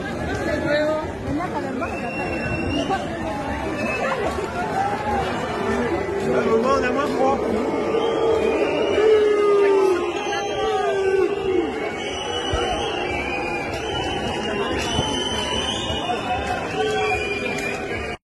Hier brennt die Polizeistation in Lorient.‌‌ Die Franzosen haben die Schnauze voll. Es geht schon lange nicht mehr um die Rentenreform alleine.